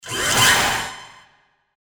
Royalty free music elements: Dramaturgical
mf_SE-2077-hf_sweep_5.mp3